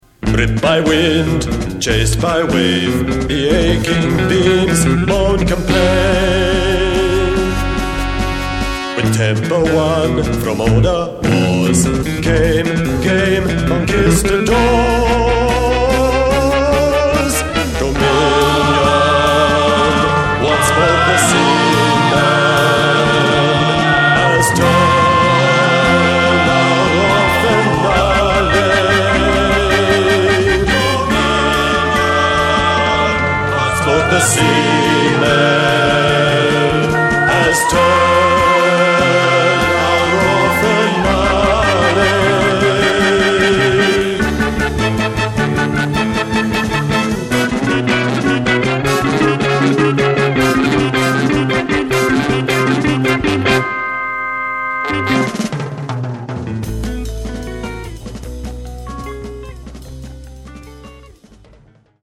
mellotron
nimble jazz rock
Digitally remastered from the original tapes